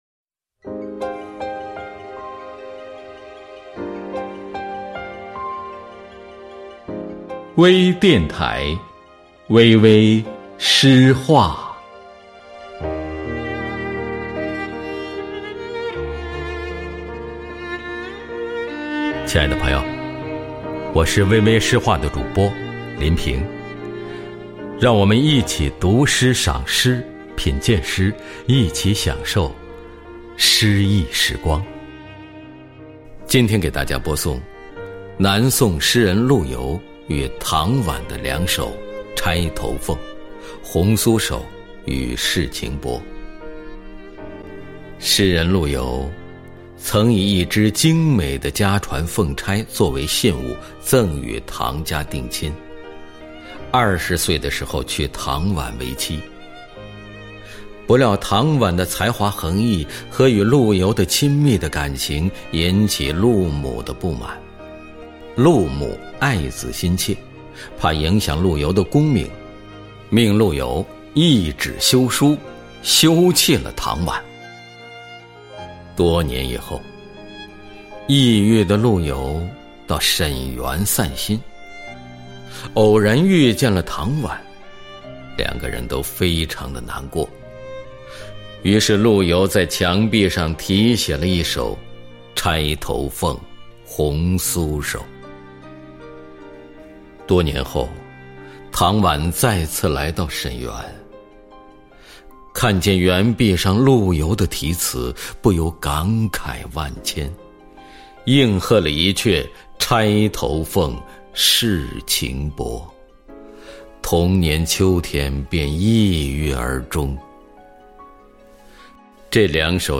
u& l7 {2 d 朗诵者/配乐